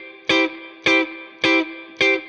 DD_StratChop_105-Cmaj.wav